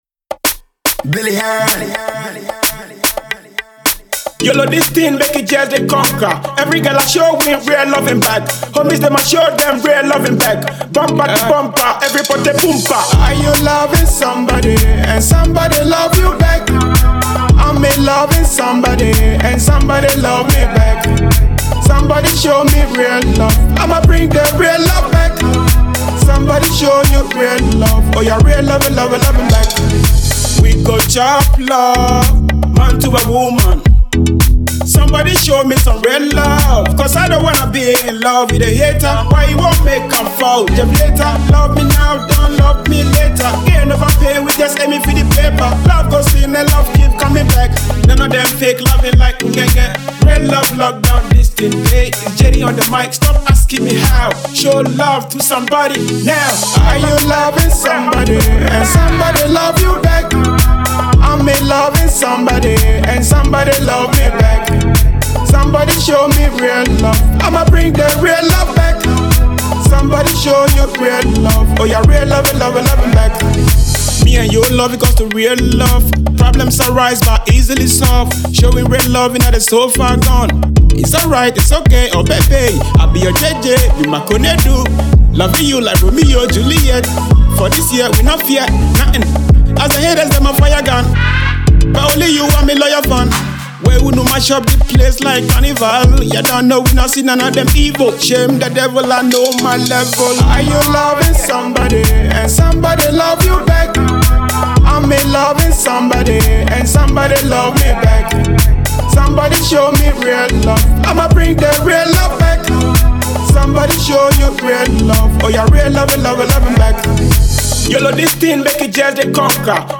GHANA MUSIC
Afrobeat song